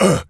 pgs/Assets/Audio/Animal_Impersonations/gorilla_hurt_01.wav at master
gorilla_hurt_01.wav